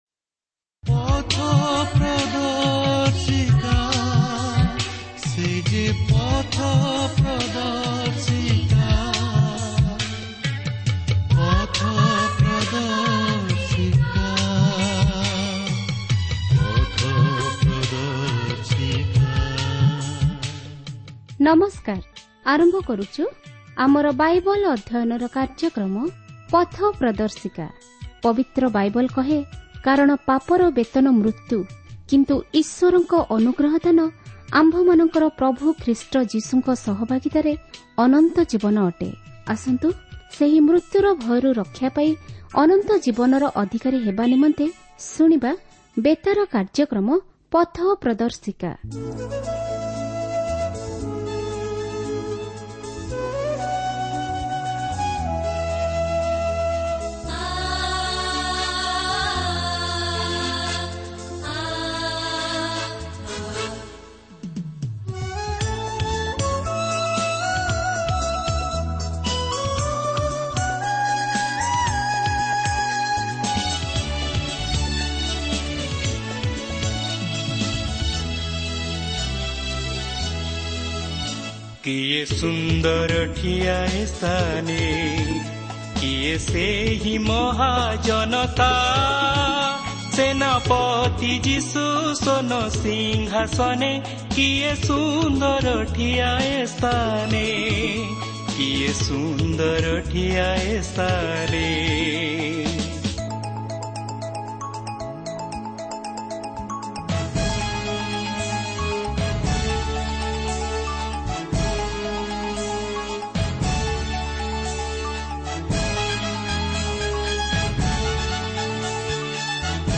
ଏହା ଧାରାବାହିକ ପ୍ରଣାଳୀଗତ କାର୍ଯ୍ୟକ୍ରମ ପ୍ରତିଦିନ ୩୦ ମିନିଟ ରେଡିଓ ଯୋଗେ ପ୍ରସାରଣ କରି ଶ୍ରୋତାମାନଙ୍କୁ ସଂପୁର୍ଣ୍ଣ ବାଇବଲ ଉପରେ ଶିକ୍ଷା କରାଯାଉଅଛି ୤